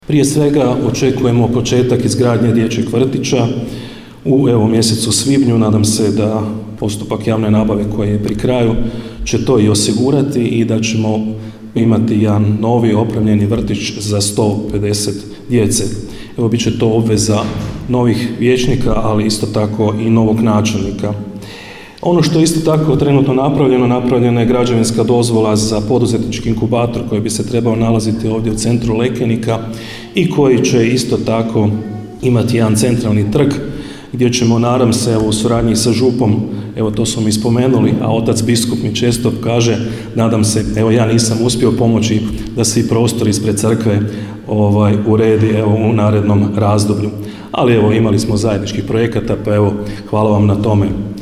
Načelnik Ivica Perović osvrnuo se na projekte koje je označio kao projekte koji su budućnost razvoja Općine Lekenik